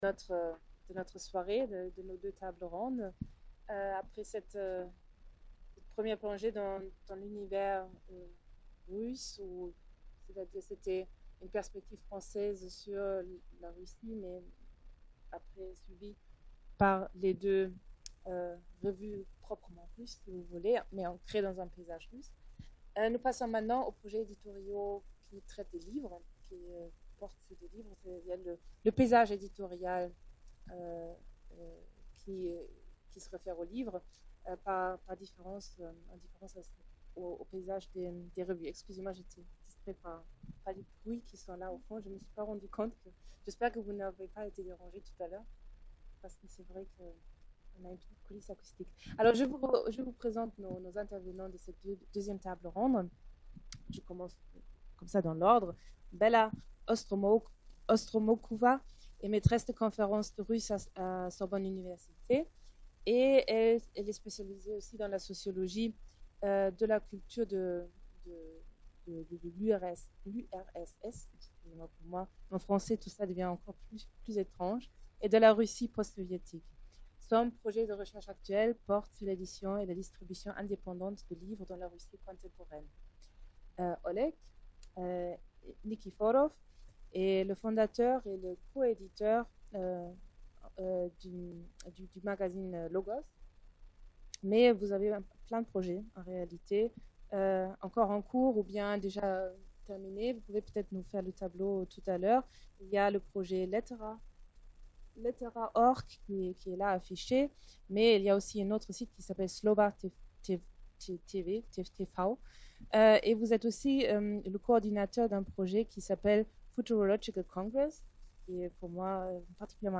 A l’occasion du salon du livre de Paris 2018 où les lettres russes sont à l’honneur, deux tables rondes réuniront des chercheurs, des traducteurs et des éditeurs de livres et de revues implantés en France et en Russie pour débattre de la circulation des textes de sciences humaines, de l’édition des traductions entre français et russe, et des enjeux linguistiques, conceptuels et politiques de ces circulations.